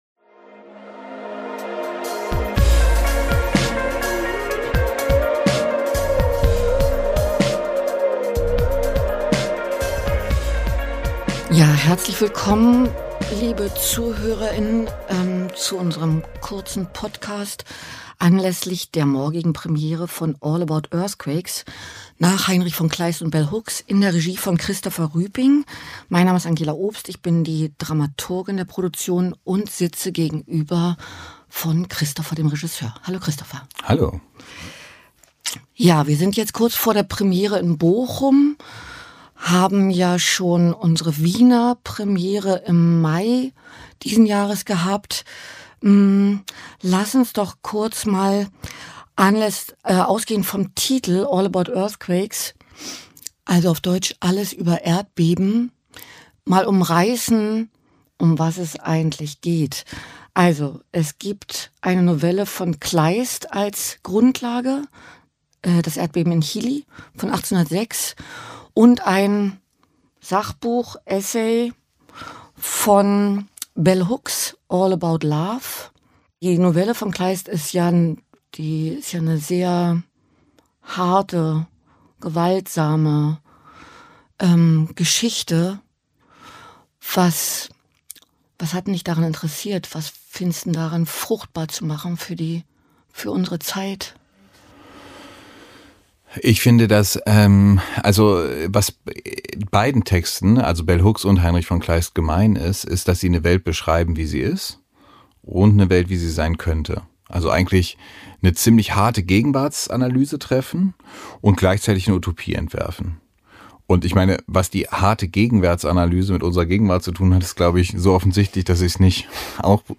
Erfahren Sie mehr über neue Inszenierungen aus dem Schauspielhaus Bochum in der neuen Episode der Talkreihe und Audioeinführung mit Künstler*innen und Dramaturg*innen der Produktion.